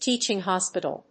アクセントtéaching hòspital